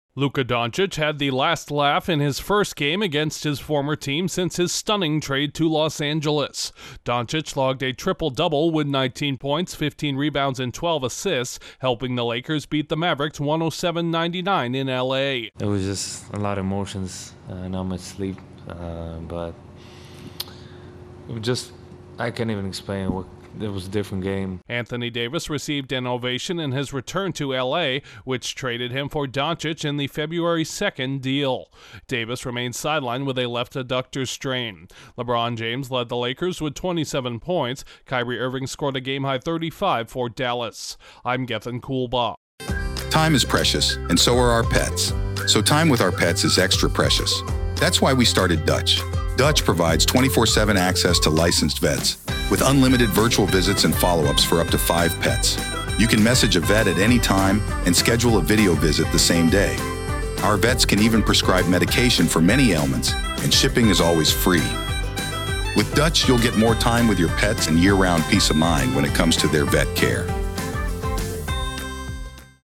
A triple-double from a recently traded NBA superstar against his former team headlines a big night in L.A. Correspondent